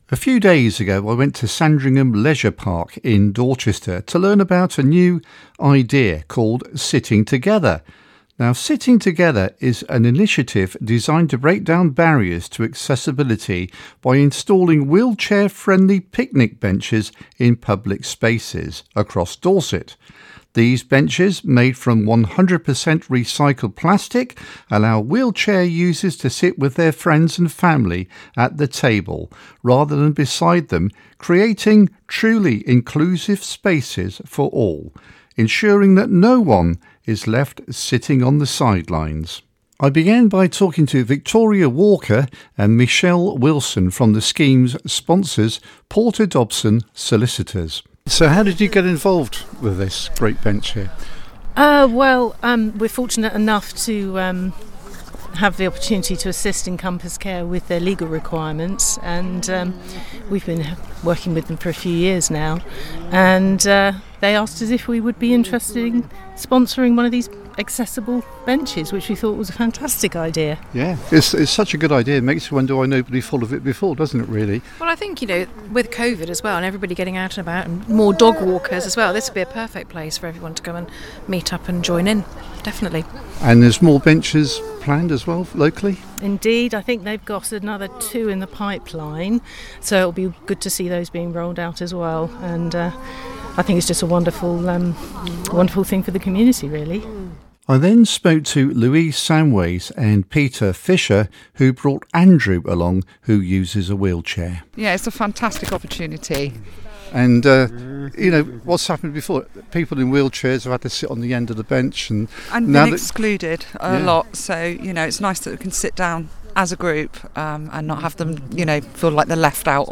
The Community Radio Station covering Central-Southern Dorset, run by volunteers and not-for-profit